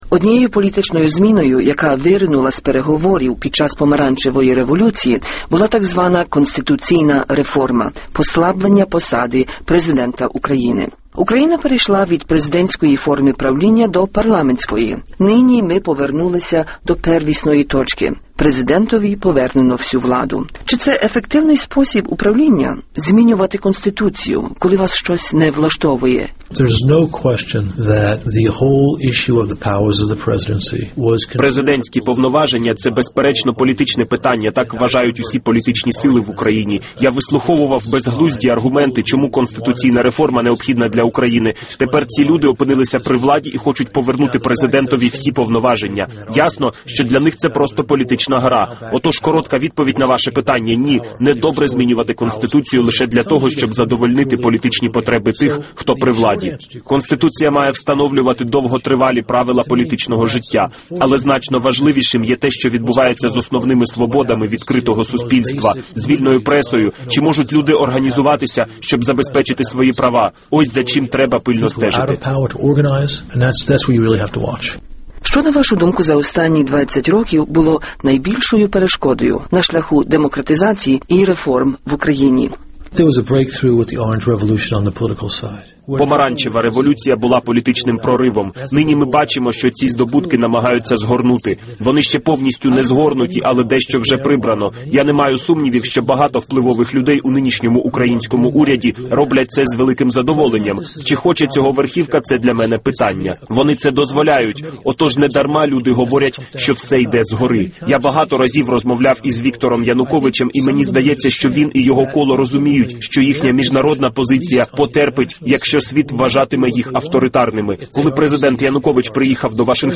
Джон Гербст – інтерв’ю до 20-річчя Незалежності